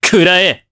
Roi-Vox_Attack4_jp.wav